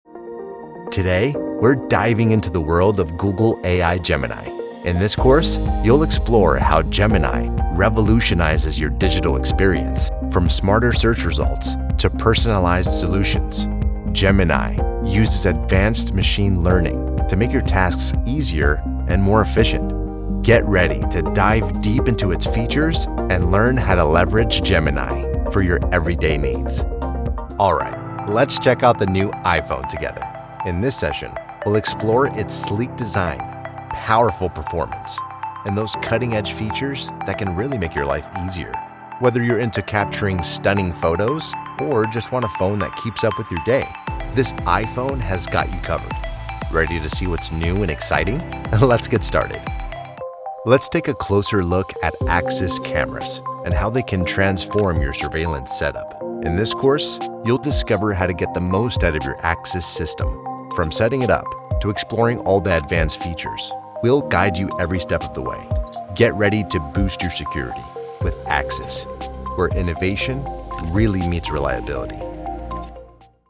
Voice-Over Artist
E-Learning
Informative, Approachable, Corporate
Elearning-Demo.mp3